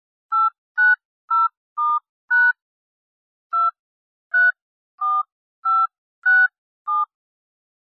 Звуки нажатия кнопки
Звук нажатия клавиш телефона с тональным набором